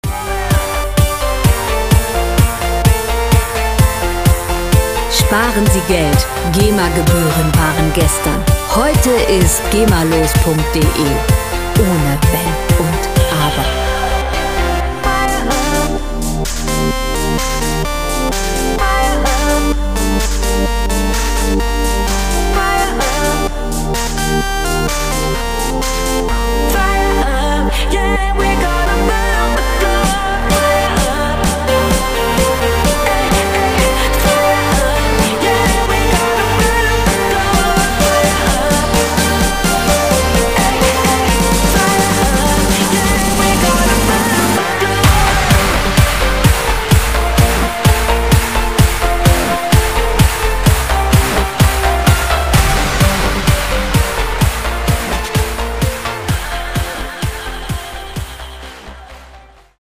• EDM Anthem